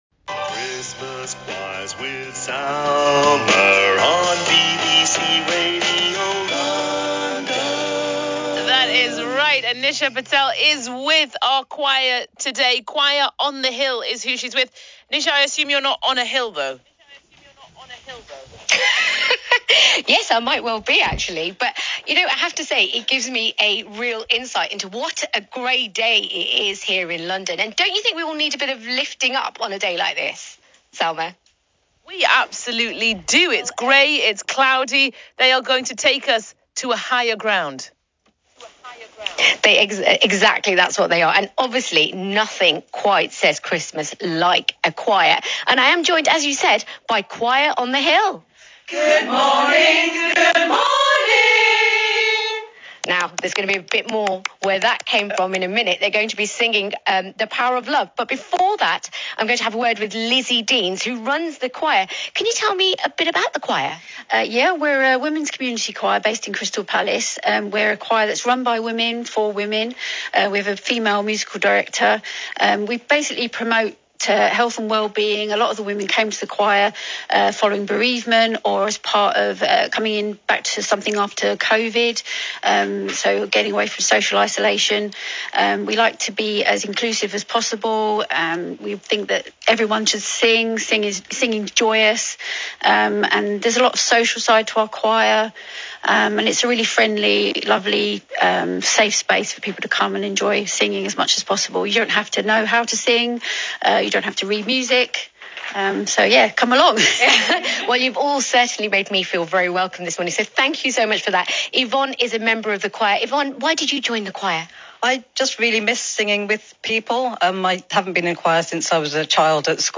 performed live on BBC Radio London